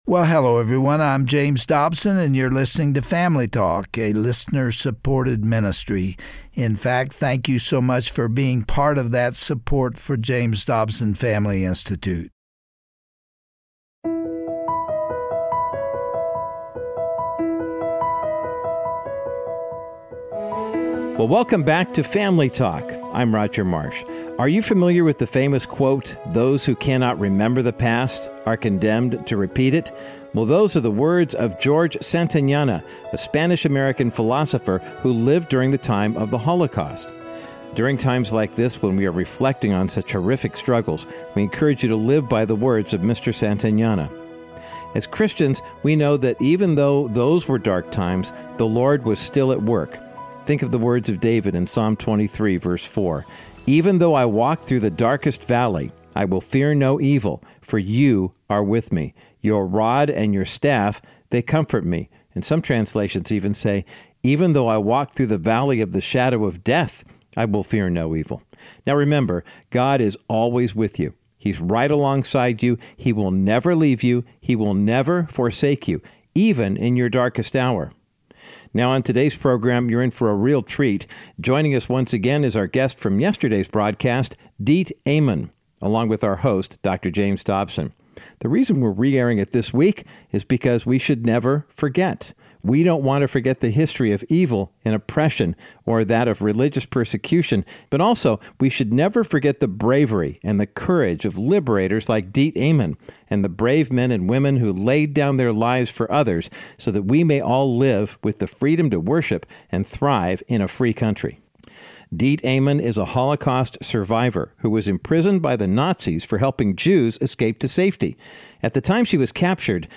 On today’s classic edition of Family Talk, Dr. James Dobson continues his heartfelt conversation with Diet Eman, a courageous Holocaust survivor, who fought alongside her fiancé and the Dutch Resistance to help Jews escape western Europe during World War II. Even in the face of evil, Mrs. Eman remained faithful to God and followed what she knew was right.
Host Dr. James Dobson